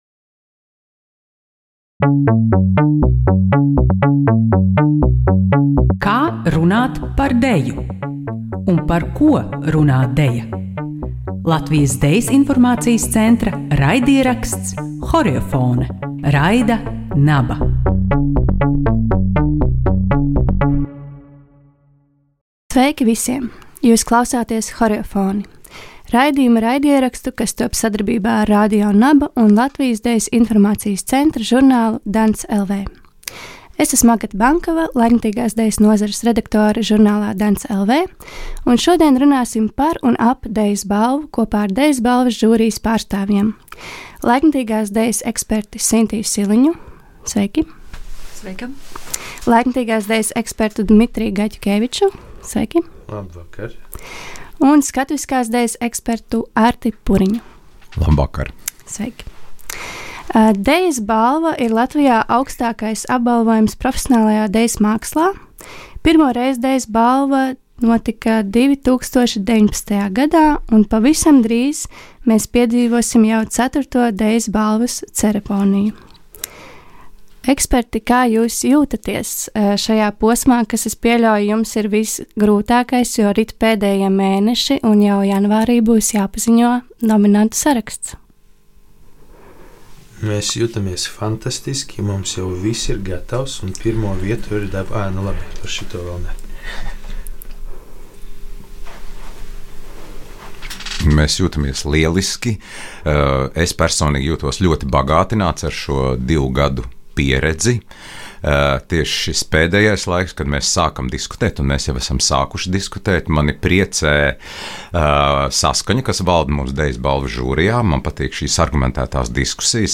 Ieraksts tapis Radio NABA studijā ar Valsts Kultūrkapitāla fonda atbalstu.